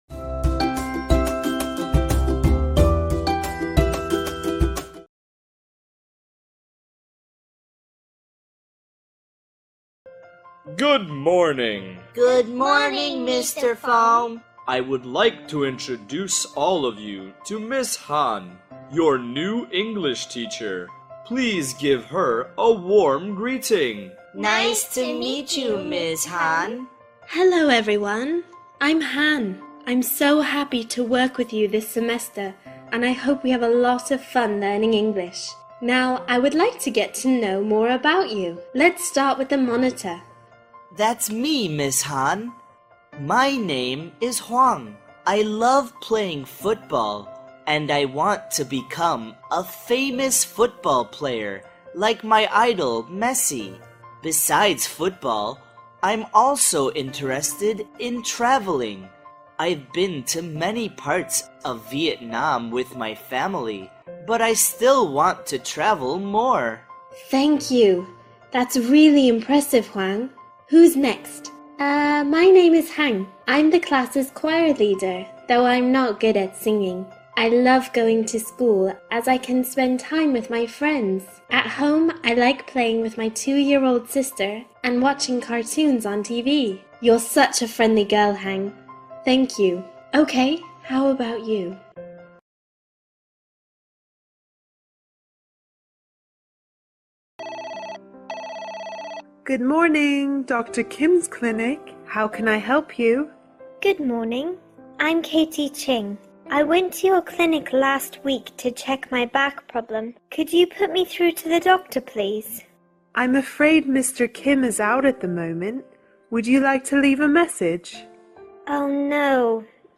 Part 2 – Making an Appointment Objective: Listening for time expressions and telephone conversations.